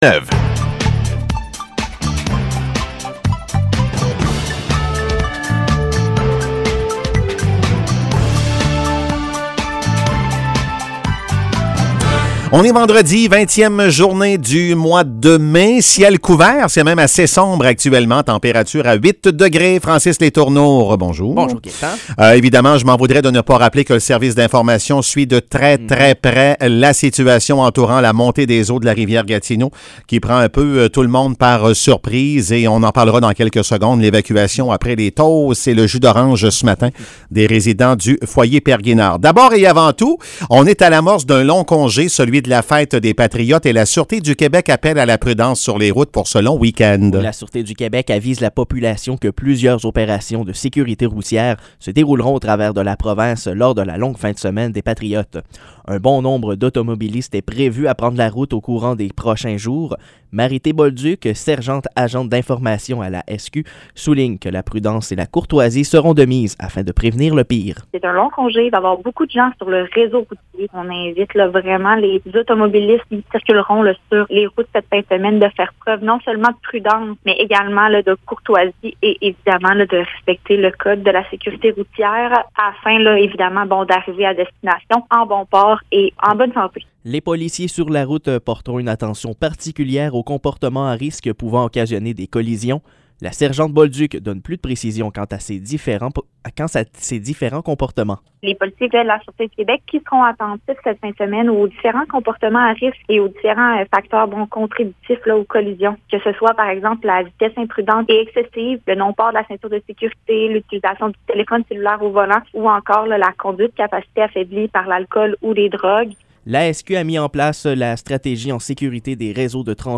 Nouvelles locales - 20 mai 2022 - 7 h